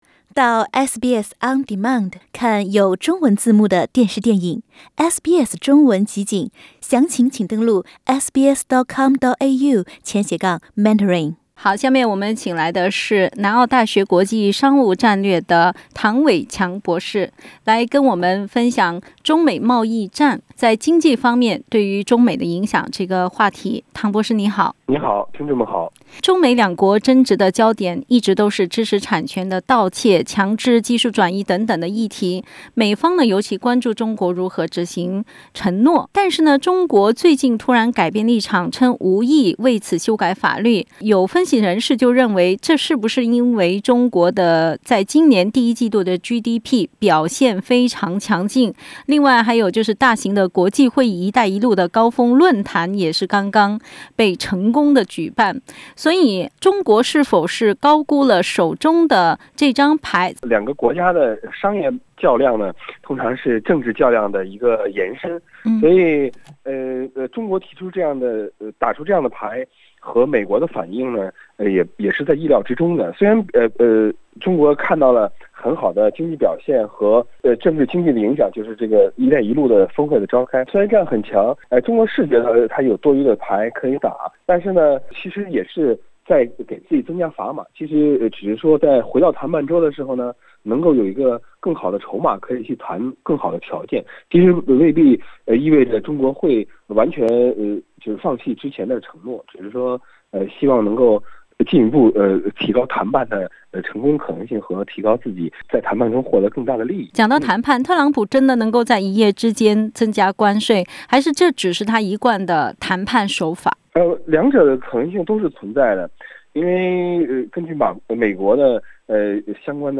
University of SA）就此話題接受本台寀訪。